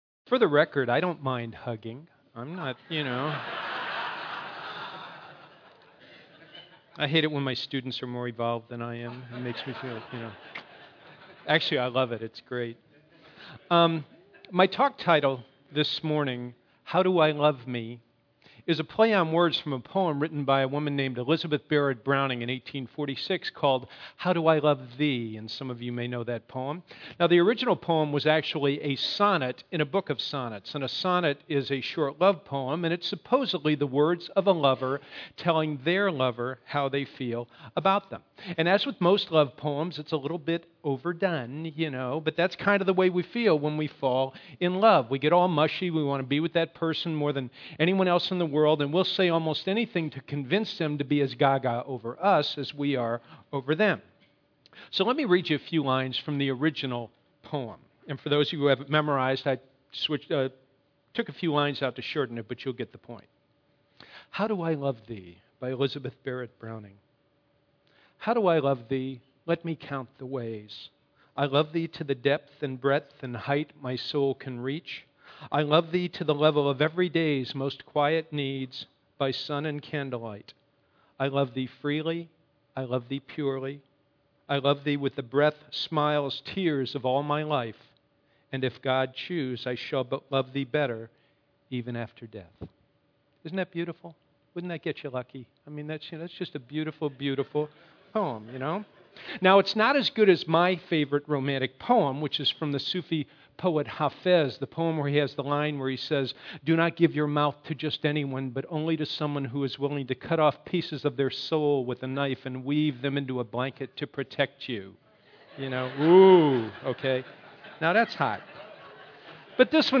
Our one-hour Sunday services are open and comfortable, with music, laughter, and interesting talks.